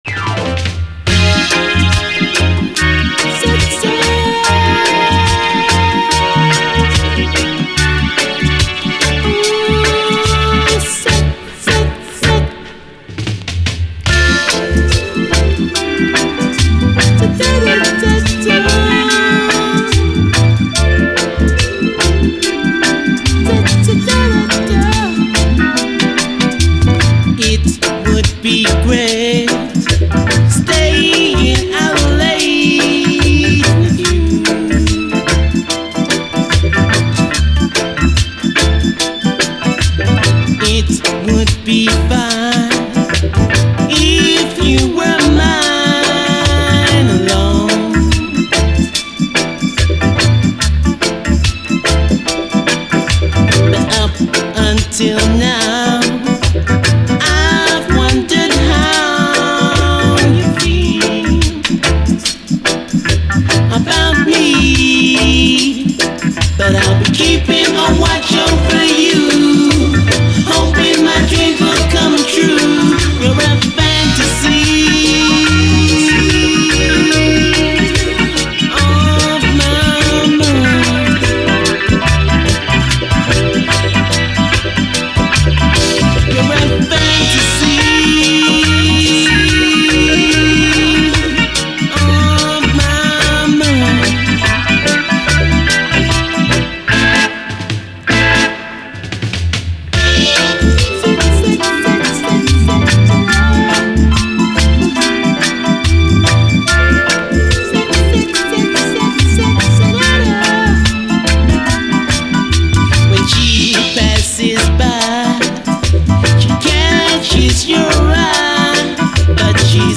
REGGAE
メロウ＆グルーヴィーな演奏も男性ヴォーカル＆コーラスも全てが繊細でハイクオリティー！
甘いシンセ＆ファルセットにトロけます。両面、後半はダブに接続し最後まで聴き逃せない。